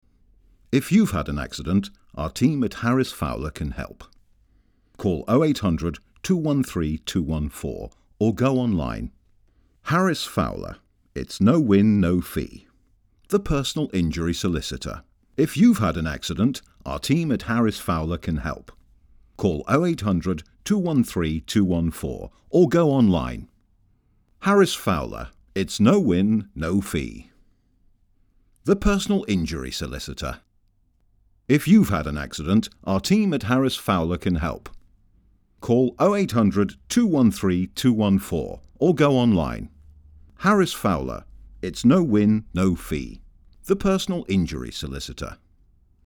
Deep rich warm voice with gravitas, versatile voice actor, characterisations, authoritative, commanding,
Sprechprobe: Werbung (Muttersprache):